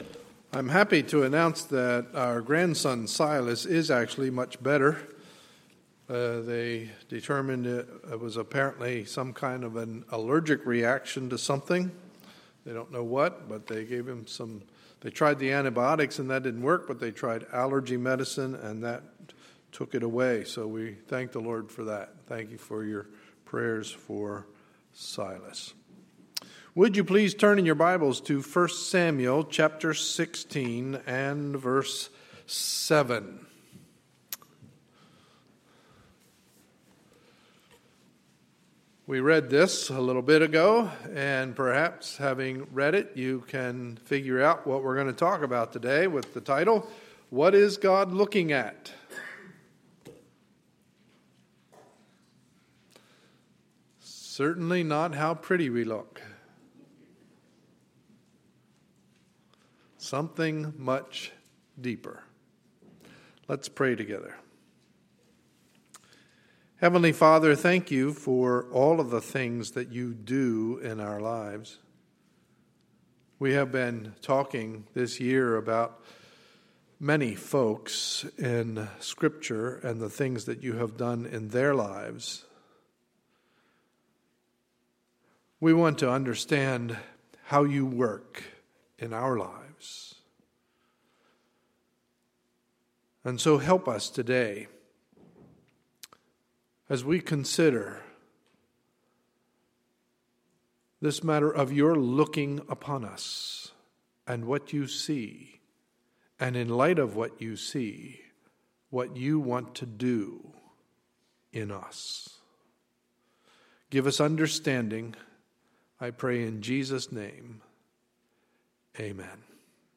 Sunday, August 25, 2013 – Morning Service